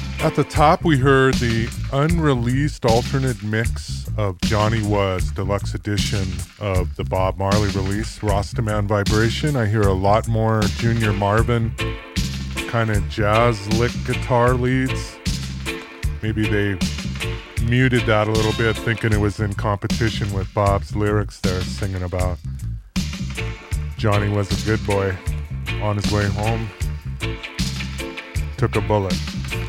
unreleased alternate album mix